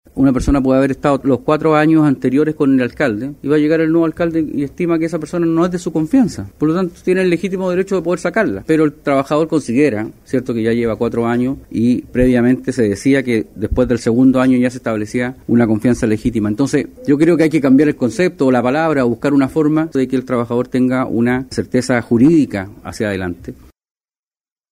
El diputado de Renovación Nacional, Frank Sauerbaum, pidió agilizar la tramitación de este proyecto, aunque admitió que se debe definir claramente lo que es “confianza legítima”.